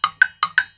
• jam block studio sample 1.wav
Recorded in a professional studio with a Tascam DR 40 linear PCM recorder.
jam_block_studio_sample_1_tJf.wav